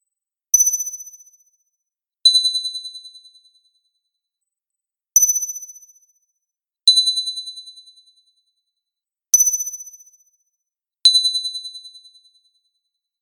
Echoing metal pings
135685 bell ding fairy glass magic metal ping sound effect free sound royalty free Sound Effects